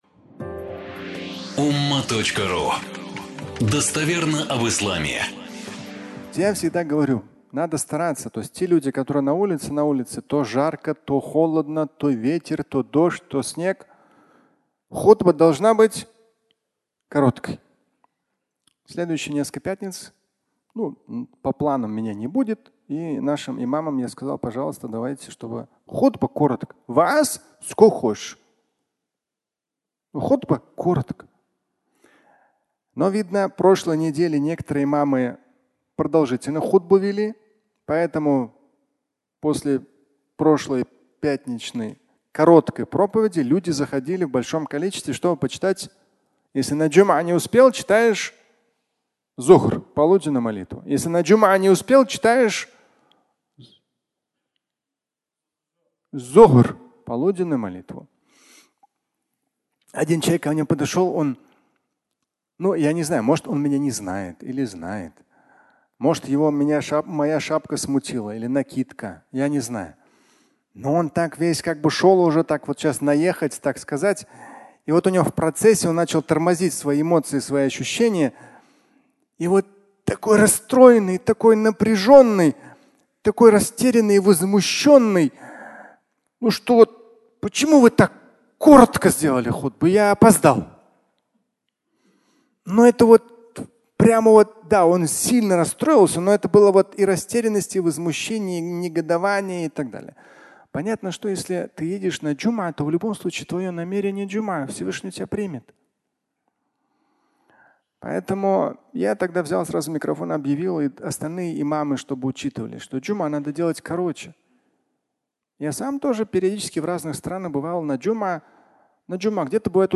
Фрагмент пятничной лекции, в котором Шамиль Аляутдинов говорит о том, что делать если опоздал на пятничную молитву.